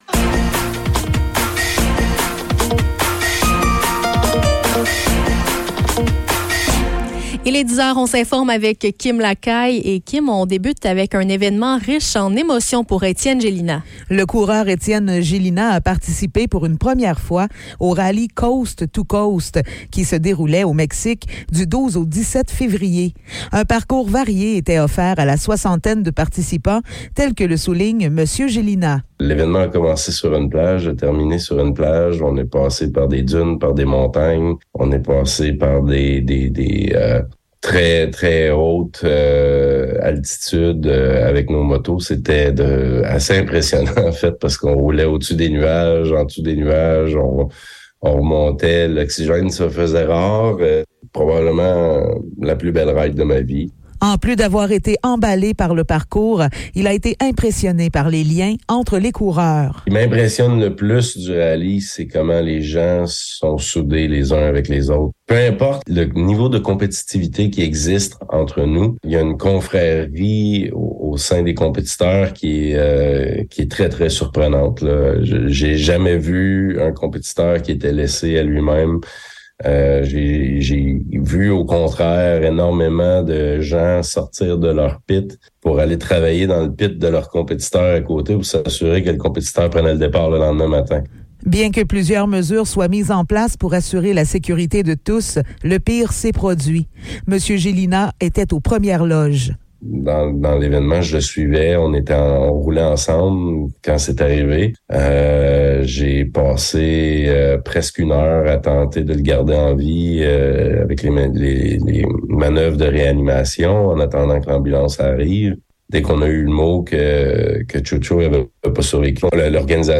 Nouvelles locales - 1er mars 2023 - 10 h